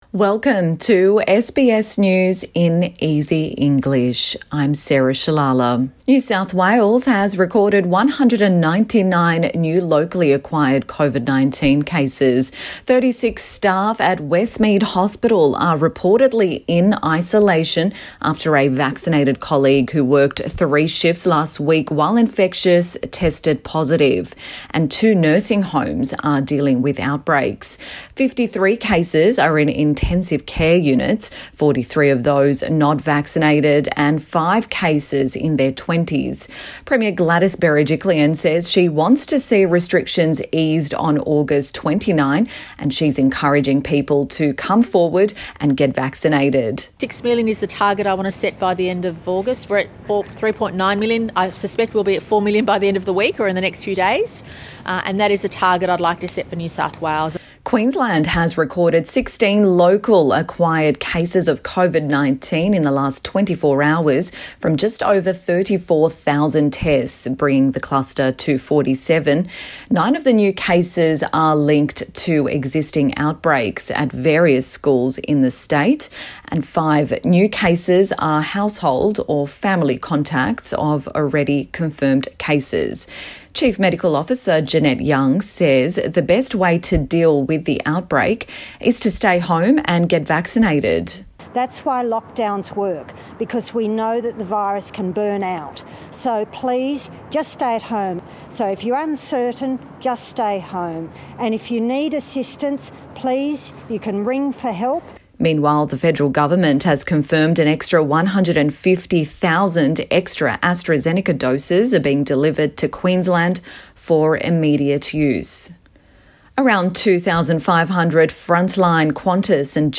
SBS News in Easy English Bulletin 3 August 2021